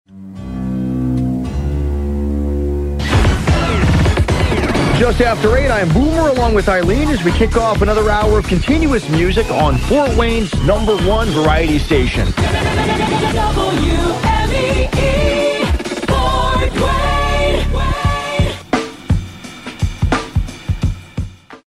WMEE Top of the Hour Audio: